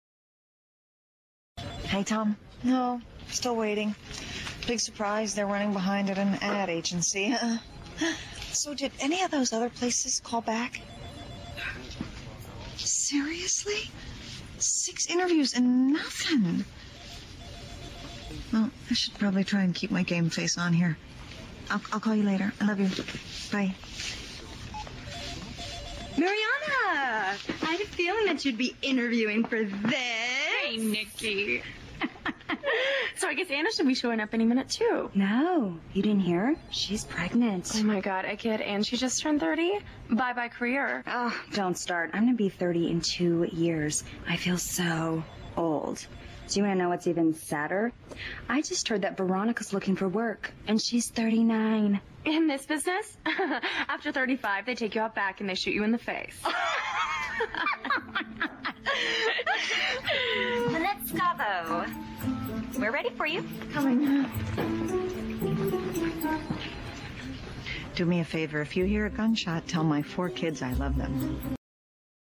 在线英语听力室影视剧中的职场美语 第130期:直觉判断的听力文件下载,《影视中的职场美语》收录了工作沟通，办公室生活，商务贸易等方面的情景对话。每期除了精彩的影视剧对白，还附有主题句型。